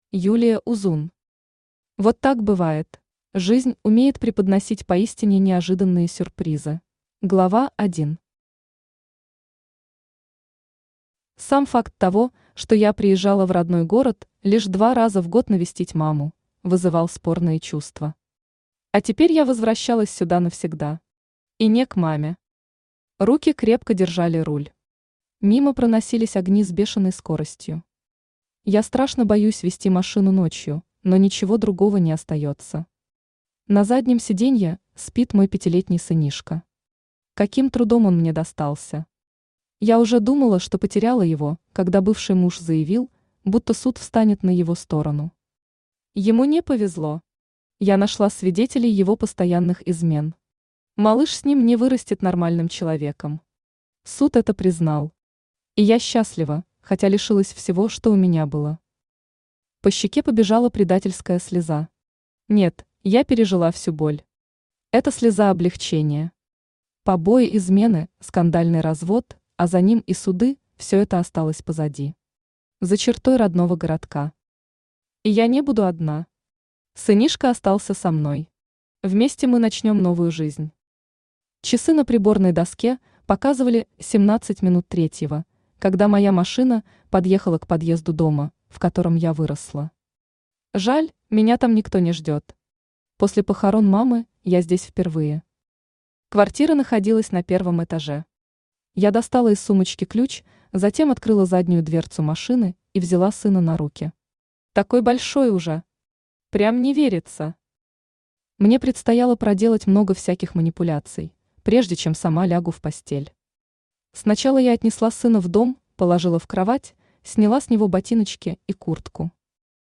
Aудиокнига Вот так бывает Автор Юлия Узун Читает аудиокнигу Авточтец ЛитРес.